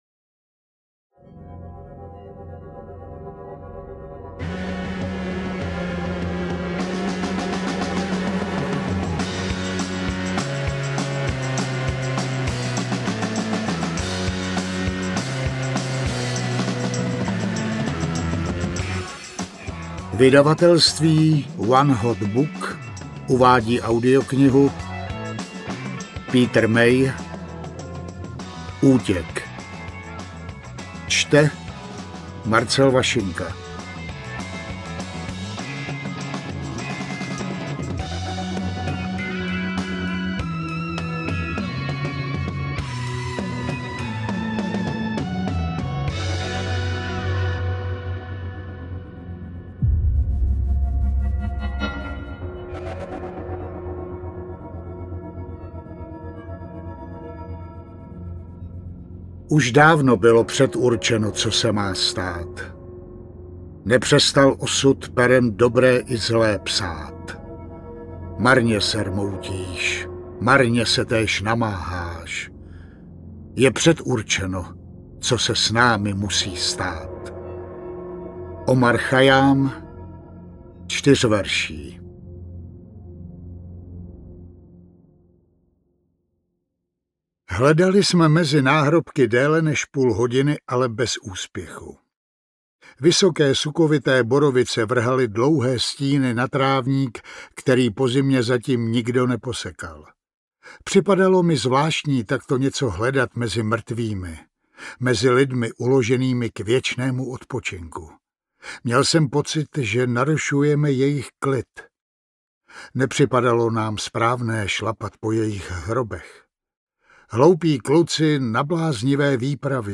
Útěk audiokniha
Ukázka z knihy